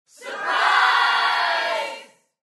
Коллеги устроили сюрприз и дружно кричат